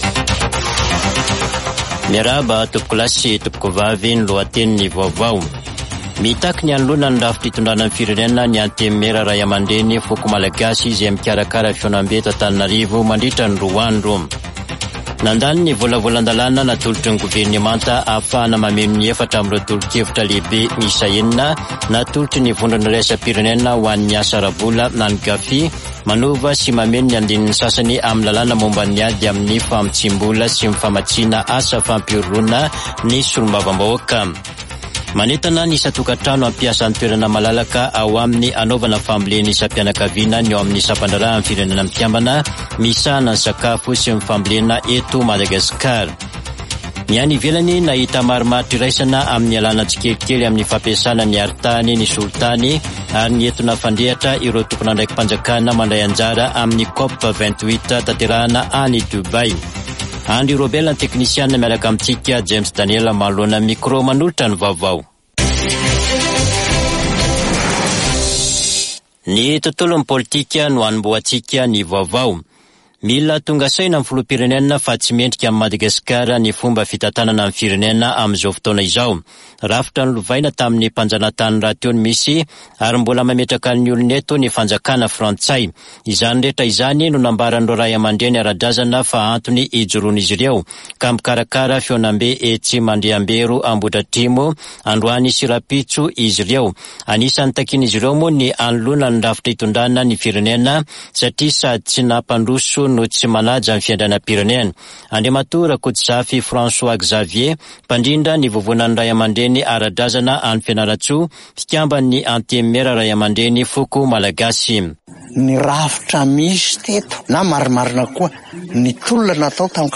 [Vaovao hariva] Alarobia 3 desambra 2023